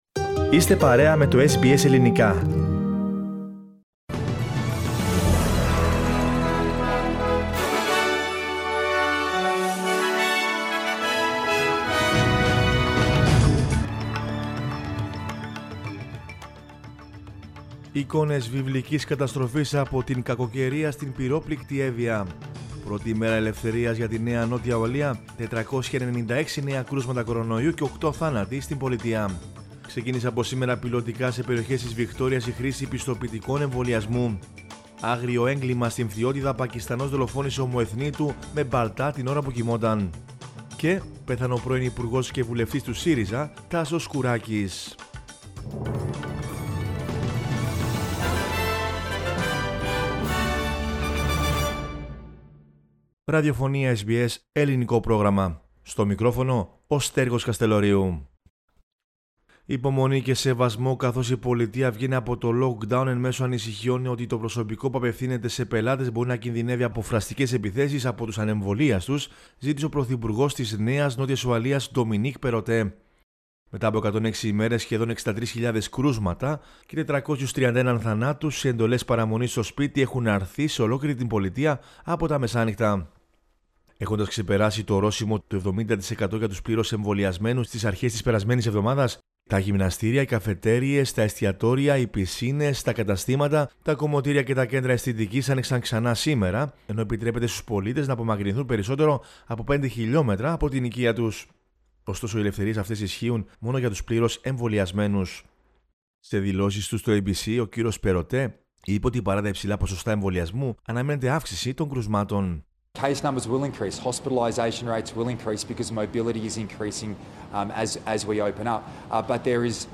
News in Greek from Australia, Greece, Cyprus and the world is the news bulletin of Monday 11 October 2021.